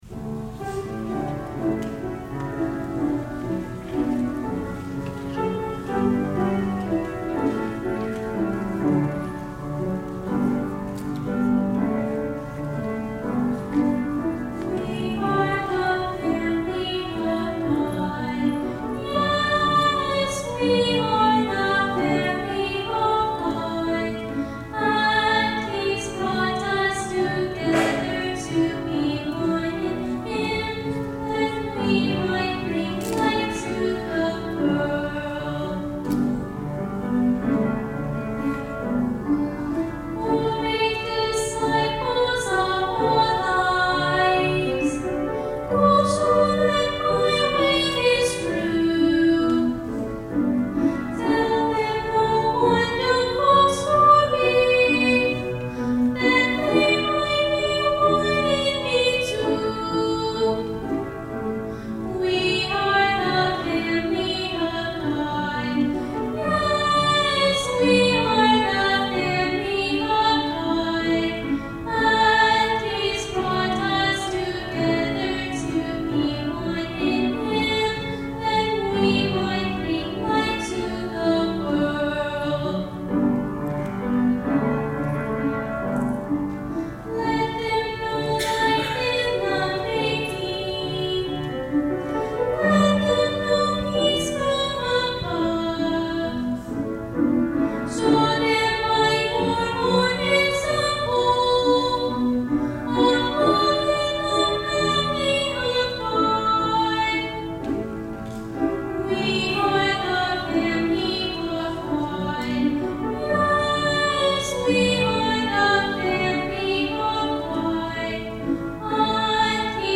soloist